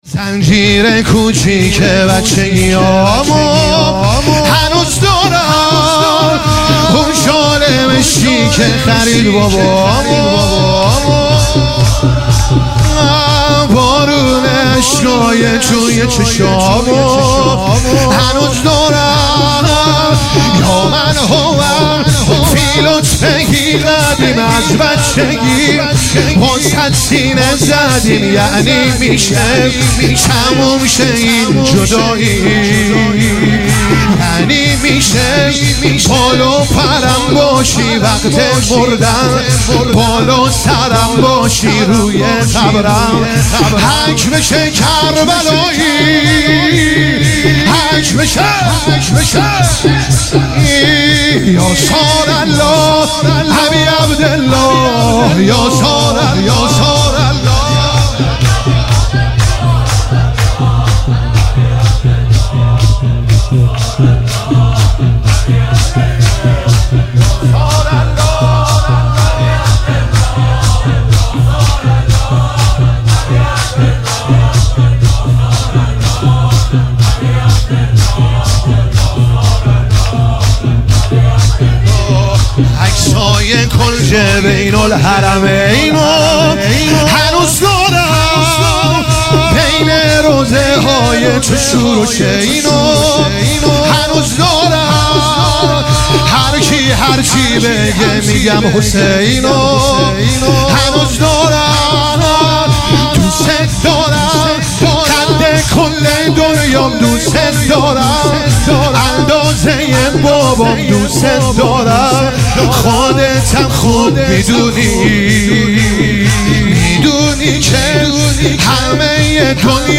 عنوان شب سیزدهم صفر ۱۳۹۹
شور